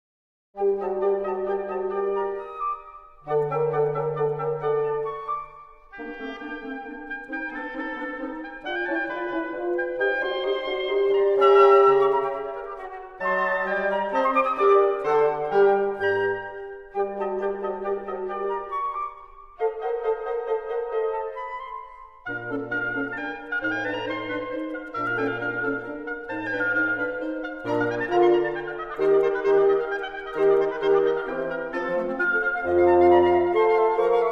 Quintett für Flöte, Oboe, Klarinette, Horn und Fagott